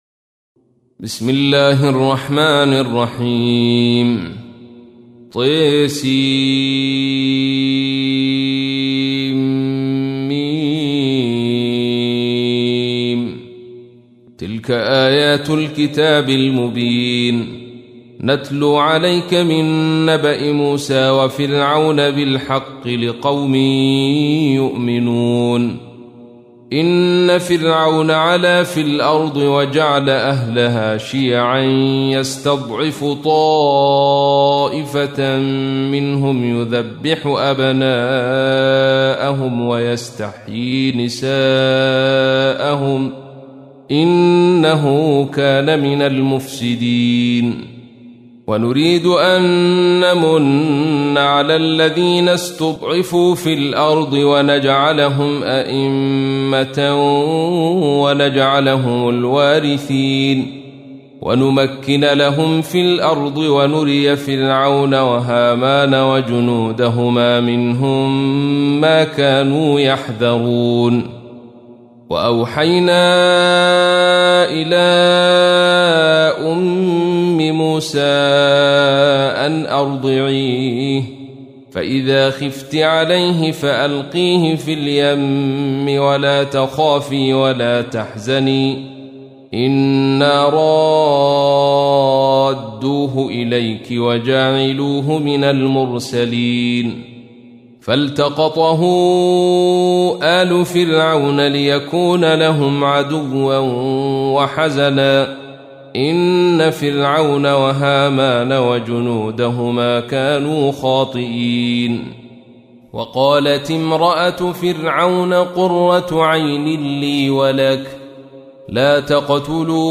تحميل : 28. سورة القصص / القارئ عبد الرشيد صوفي / القرآن الكريم / موقع يا حسين